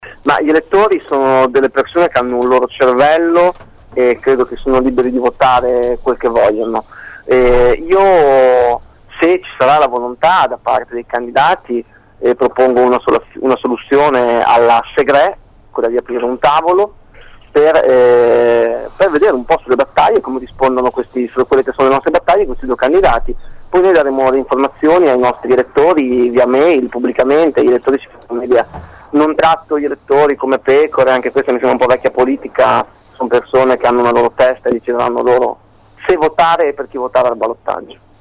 Ascolta l’intervista favia-su-ballottaggio